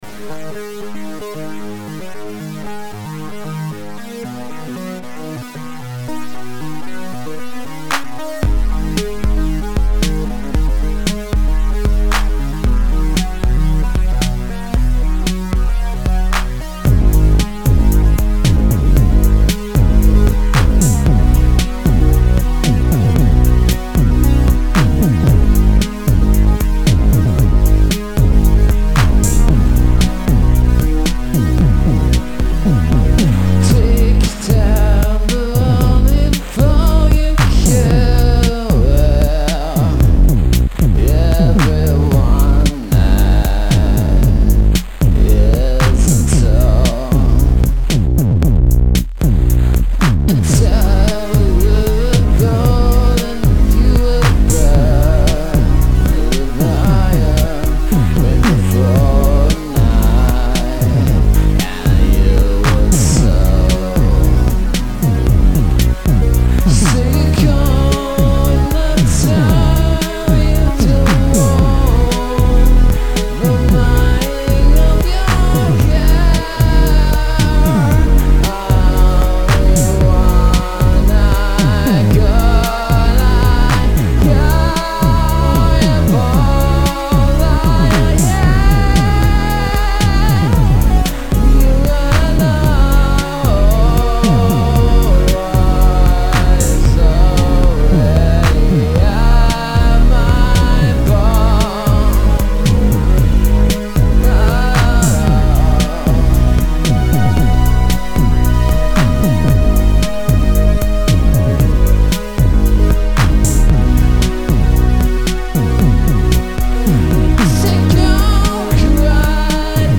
I wanted to do something nasty and dirty, so here is my stumbling attempt towards that.
There's even a bitcrusher running through a send/return channel on my voice (once again improvising, only with slight hesitance due to my roommate being in the next room as I screech it).
Amazing drums, amazing bass, amazing vocals.